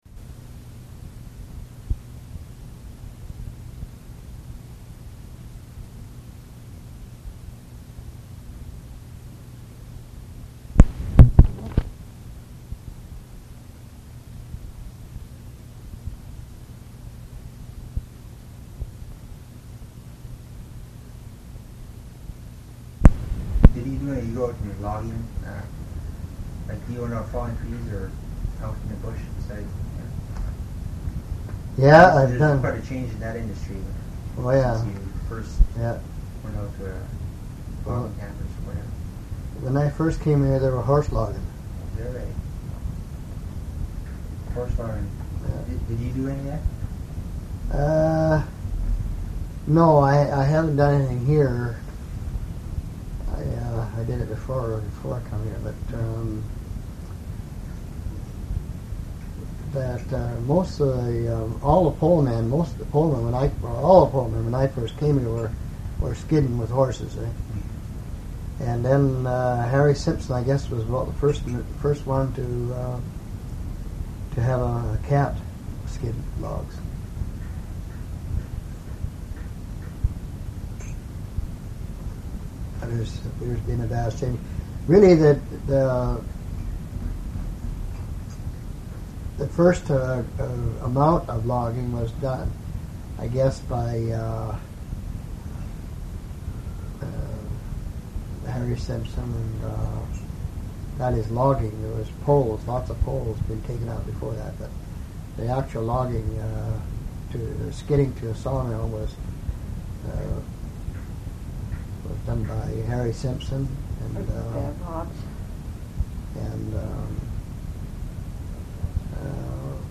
Interview - Part 3